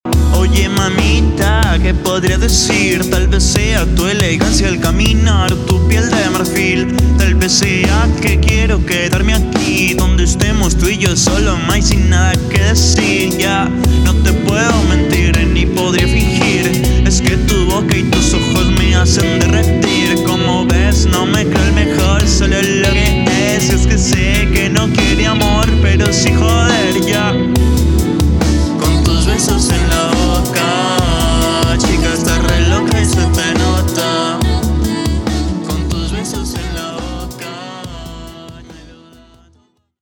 Género: Rap / Latin Rap.